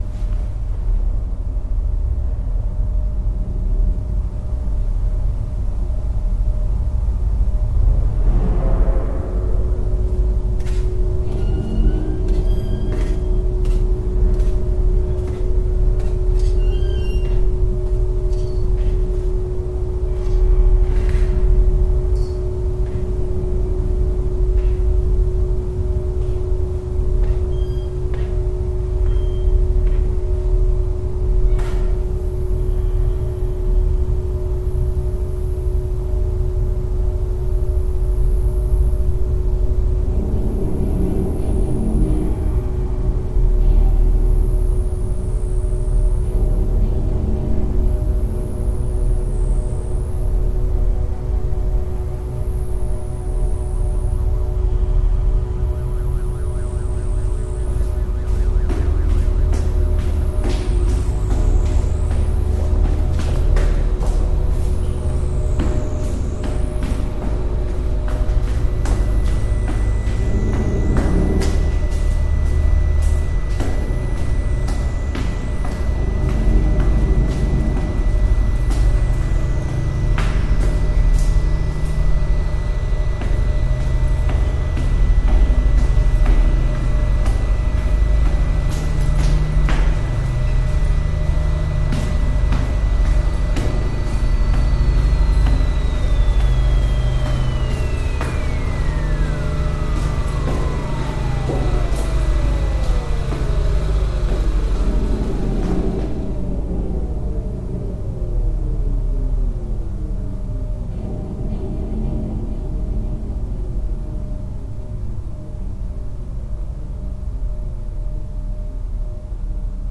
Layered field recordings plus treated Korg MS-20 tracks.